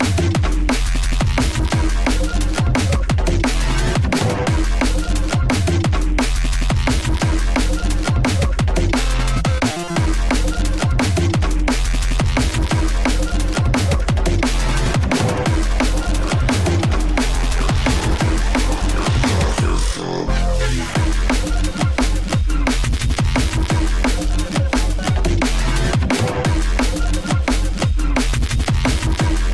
TOP >Vinyl >Drum & Bass / Jungle
TOP > Vocal Track